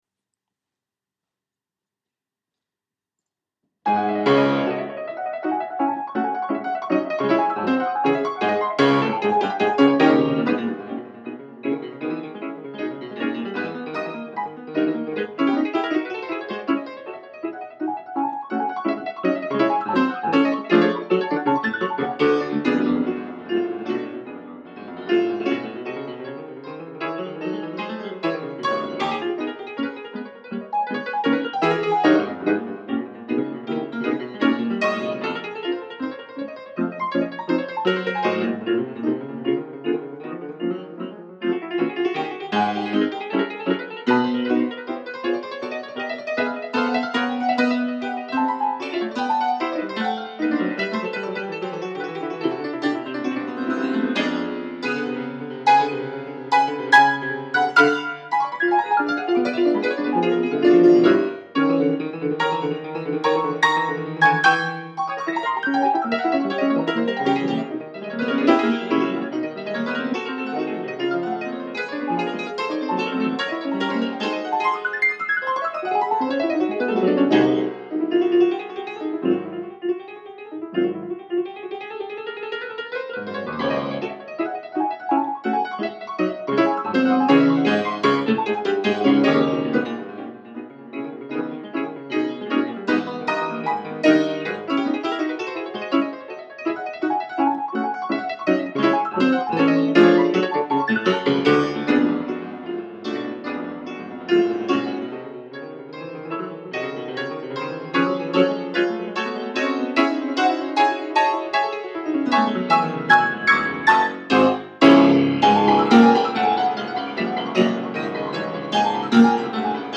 Etude in C# Minor